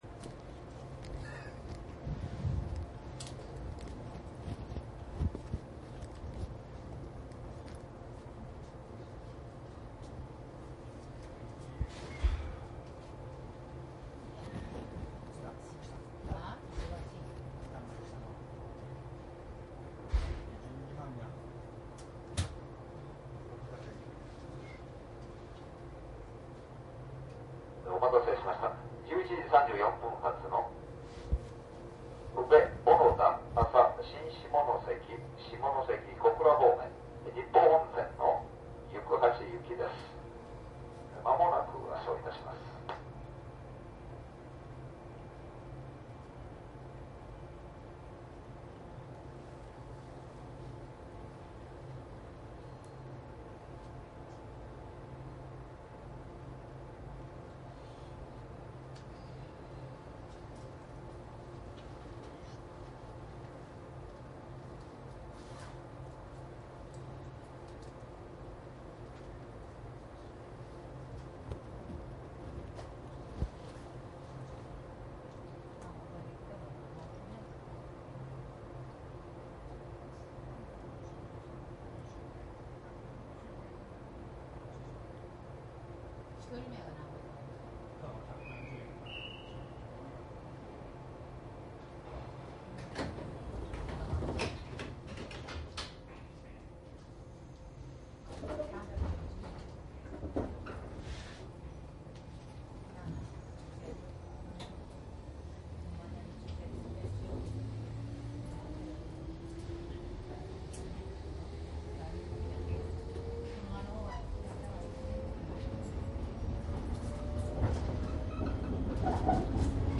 山陽本線 下り キハ４０・４７／４１５系 走行音ＣＤ♪
電化区間でディーゼルを録音。
JR九州からの乗り入れで４１５系も一部収録。
■【普通】小郡→宇部  モハ414-118
マスター音源はデジタル44.1kHz16ビット（マイクＥＣＭ959）で、これを編集ソフトでＣＤに焼いたものです。